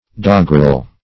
\Dog"grel\